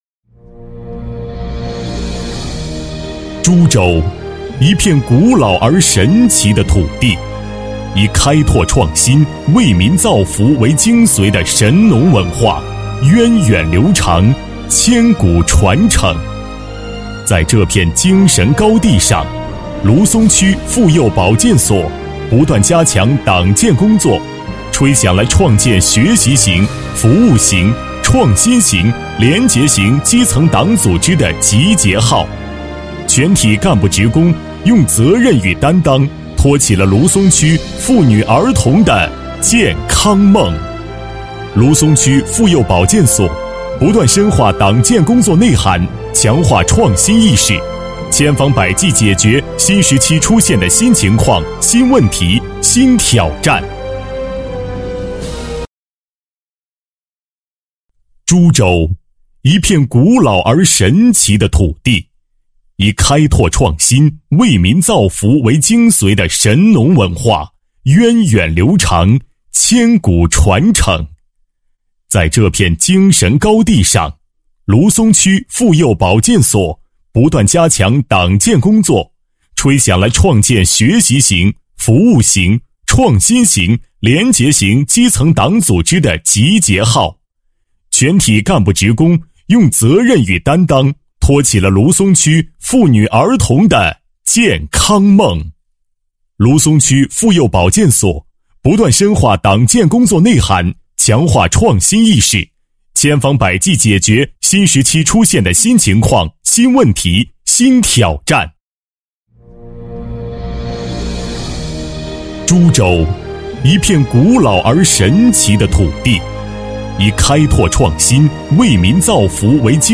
国语中年大气浑厚磁性 、沉稳 、男专题片 、宣传片 、80元/分钟男S331 国语 男声 专题片-眉山启航教育培训学校简介-专题片-浑厚、大气 大气浑厚磁性|沉稳